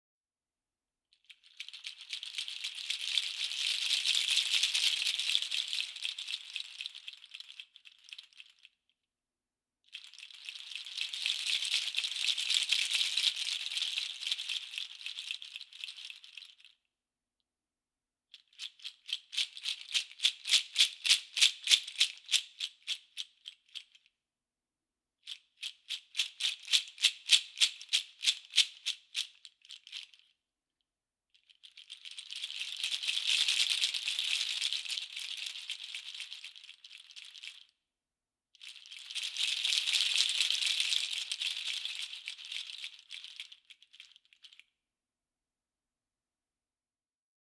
Bendo seed nutshells, attached to a rod, create a unique, versatile instrument.
Due to its clear and crisp sound, the Meinl Sonic Energy Bendo Rod Shaker stands out from the masses and is perfect for relaxing sound baths and musical sessions.